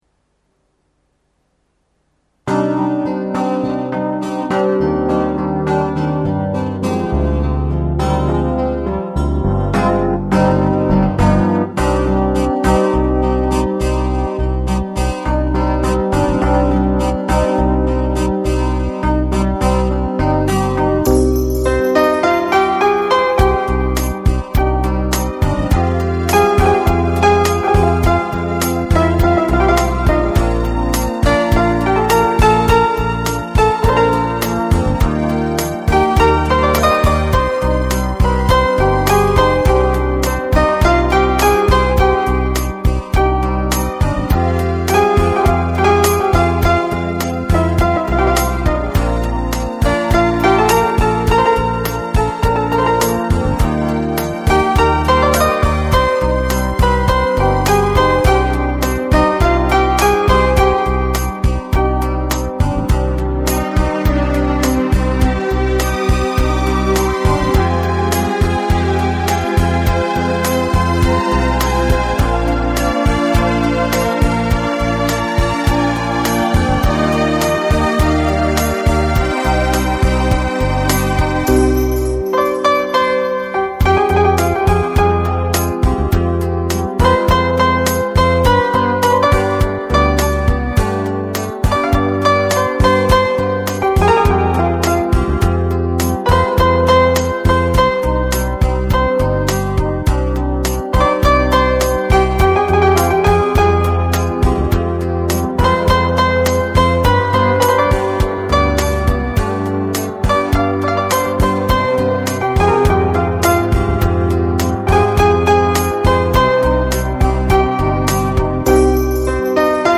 Instrumental Songs